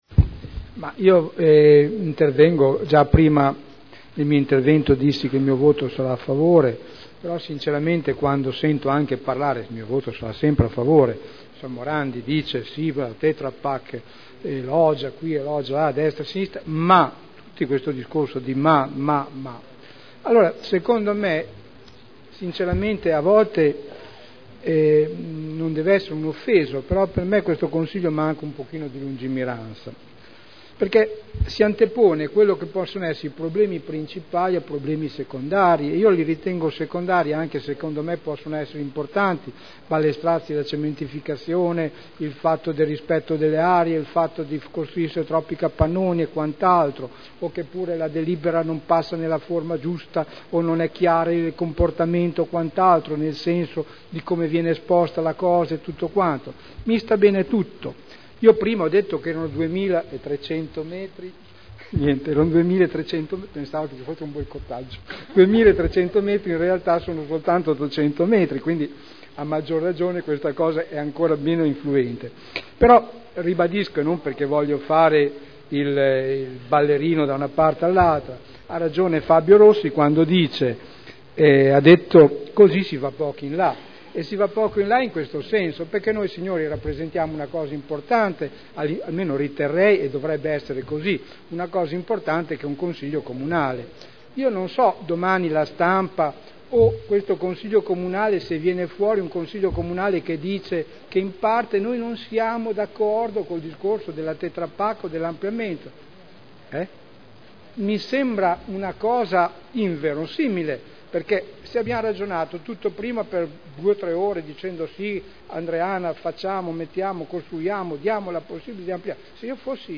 Seduta del 13/12/2010 Deliberazione: Variante al P.O.C.-RUE – AREA in via Emilia Ovest Z.E. 1481-1502 – Adozione Dichiarazioni di voto